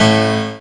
55y-pno12-e4.aif